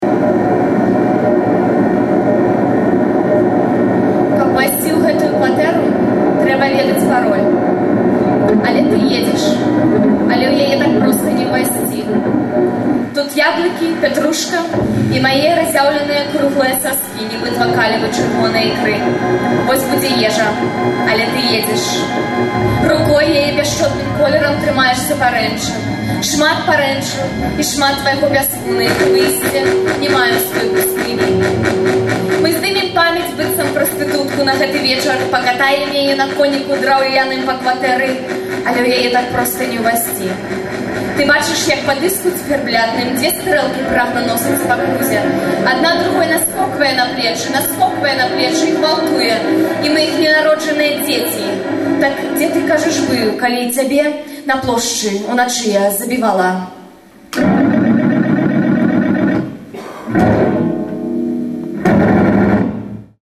Вальжына Морт чытае свае вершы
(гітара + harmonizer + delay)
Гэта фрагмэнты выступу на фэсце “няіснае беларускае мастацтва” (20.05.06, Кемніц, Нямеччына).